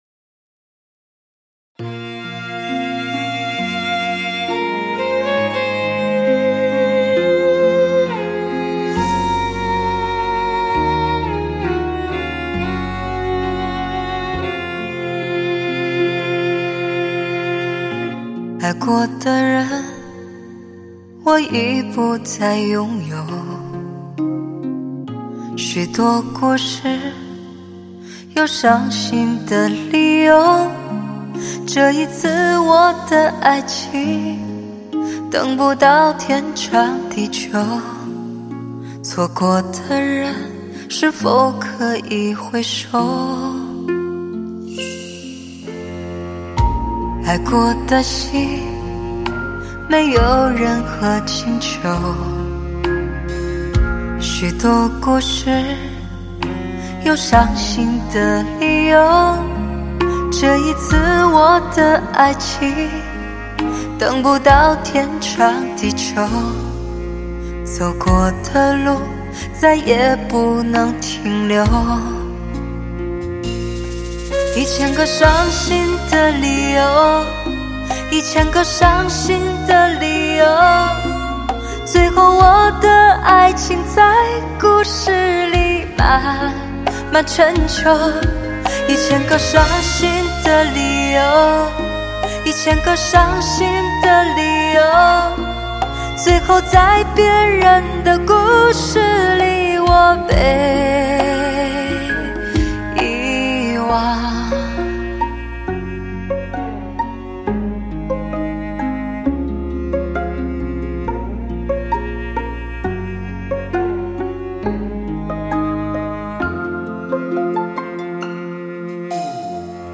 穿透灵魂的磁性音色演绎的歌曲别有一番韵味
如古筝、笛、箫、埙、手风琴、古他、大提琴、小提琴、长笛等等，都只为打造不同的试听盛宴。